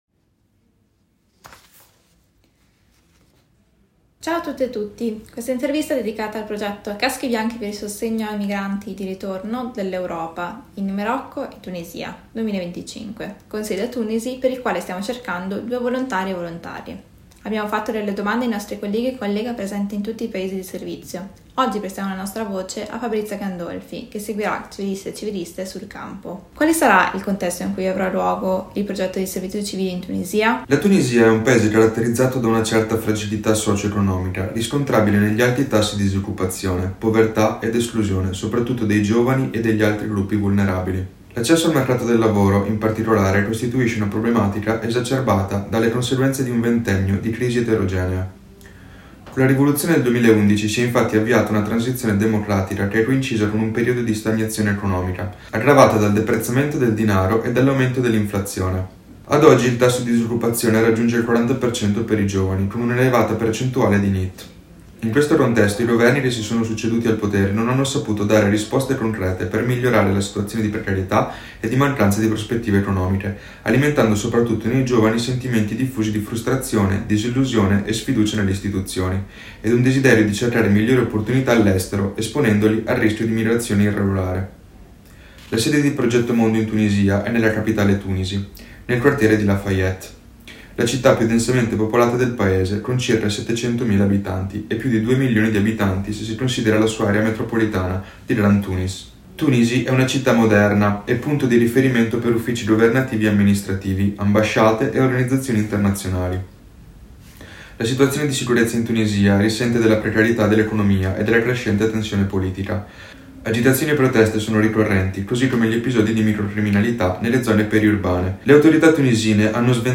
INTERVISTA 4